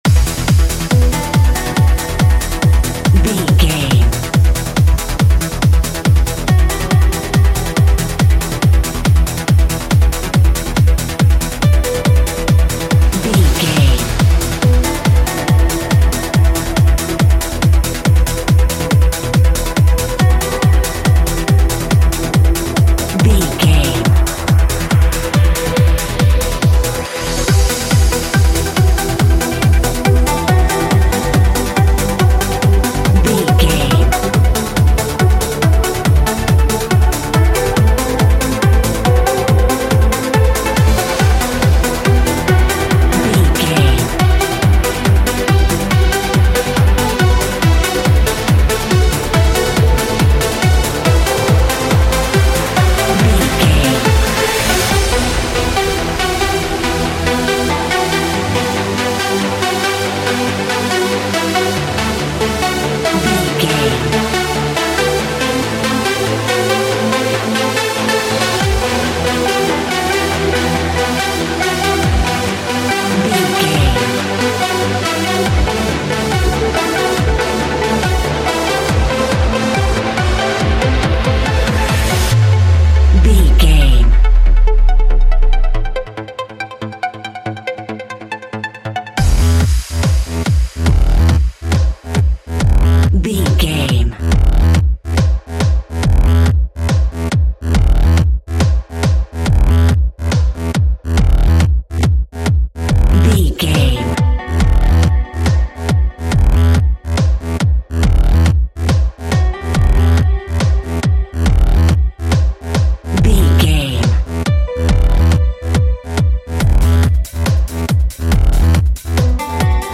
In-crescendo
Aeolian/Minor
Fast
driving
uplifting
hypnotic
industrial
mechanical
drum machine
synthesiser
acid house
electronic
uptempo
synth drums
synth leads
synth bass